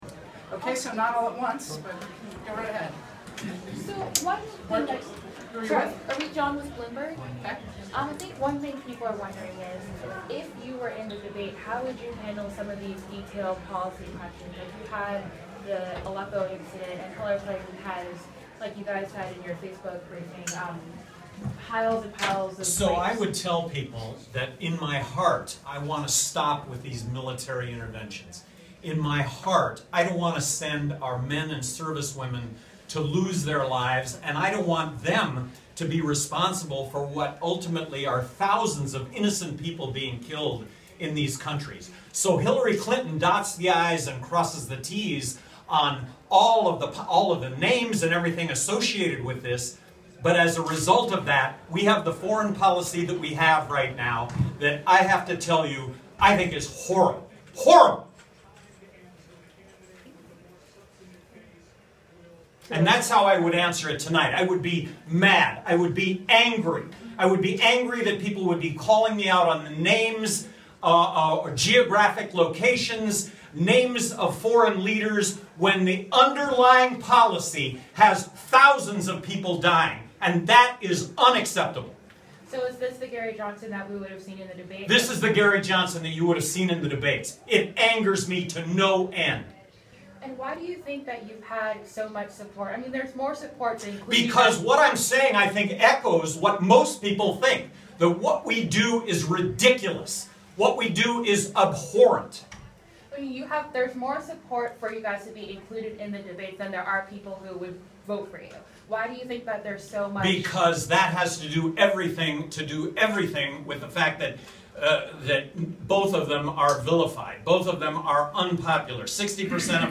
Gary Johnson Gets ANGRY Over Foreign Policy And Exclusion From Debates (You Will Too)!
LP candidate and running mate Bill Weld go ballistic over dumb wars, exclusion from debates, and more in exclusive video.